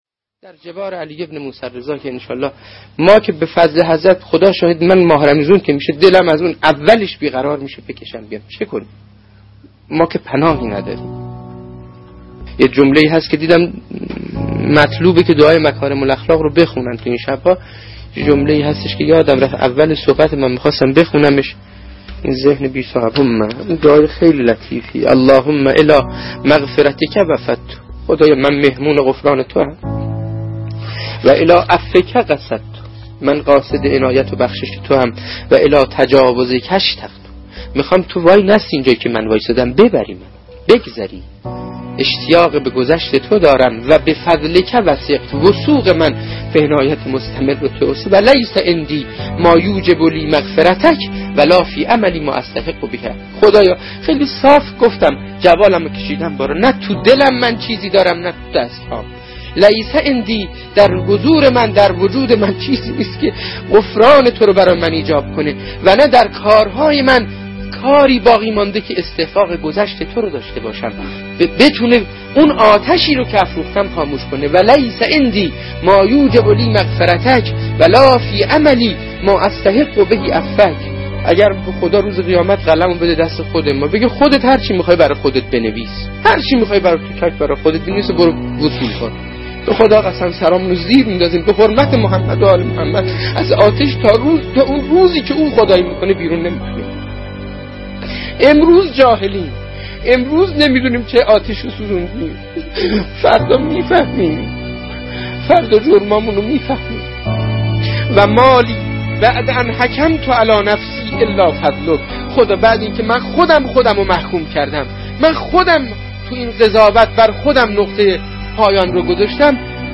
دعای-بیستم-صحیفه-سجادیه.mp3